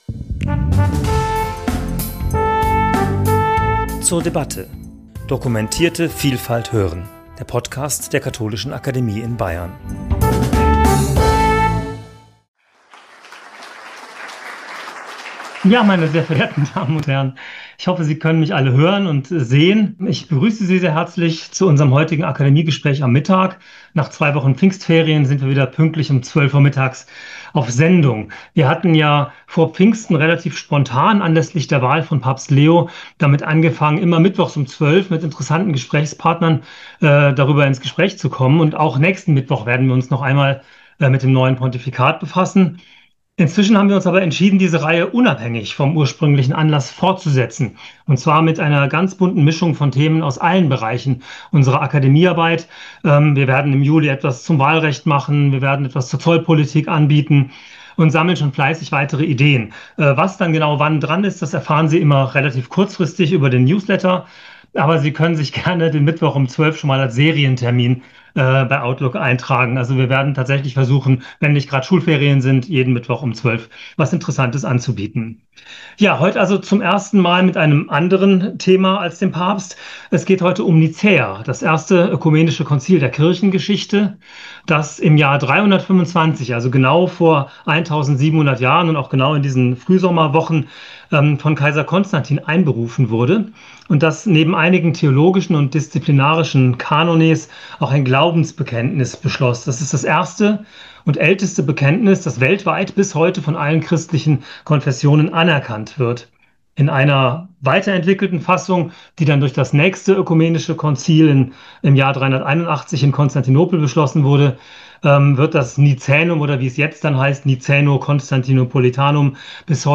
Gespräch zum Thema '1700 Jahre Nizäa - Christologische Perspektiven' ~ zur debatte Podcast